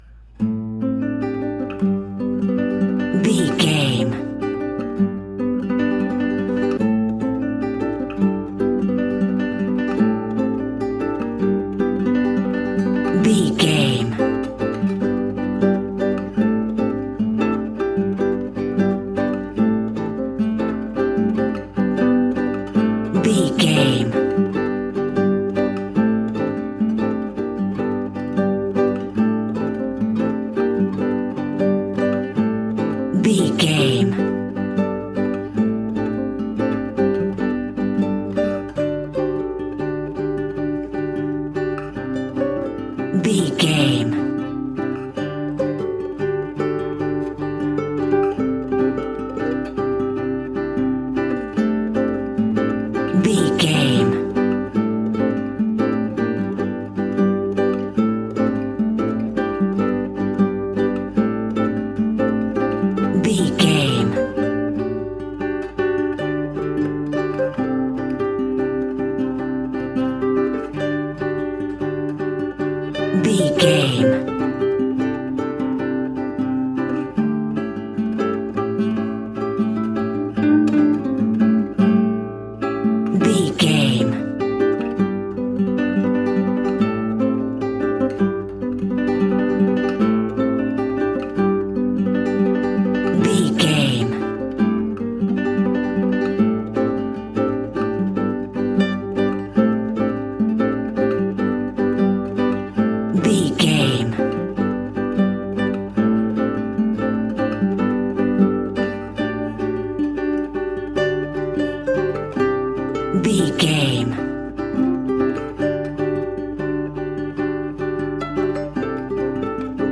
Aeolian/Minor
maracas
percussion spanish guitar